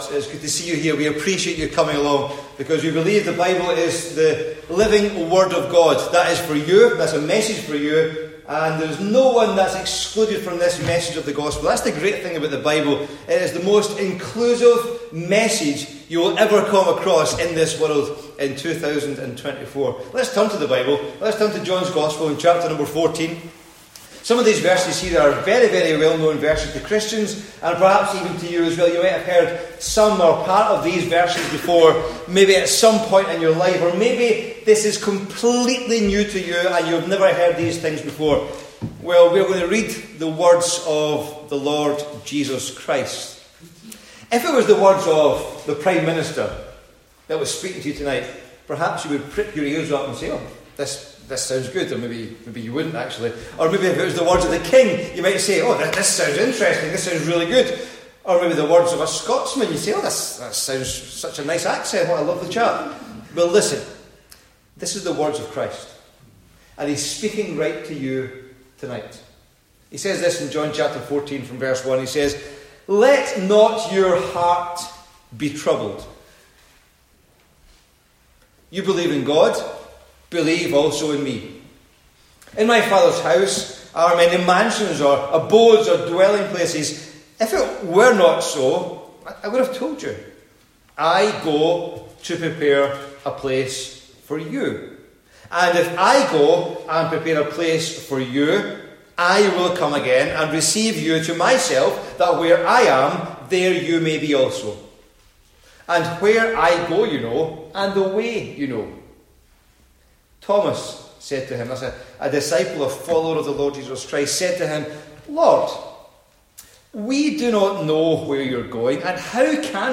Gospel Meetings